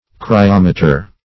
cryometer \cry*om"e*ter\, n. [Gr.